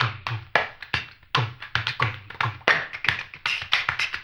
HAMBONE 10-L.wav